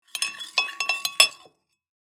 Spoon-in-glass-stirring-drink.mp3